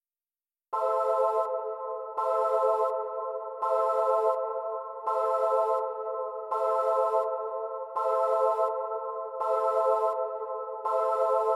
胜利的演讲 高音合成器 83 BPM
Tag: 83 bpm Hip Hop Loops Synth Loops 1.95 MB wav Key : Unknown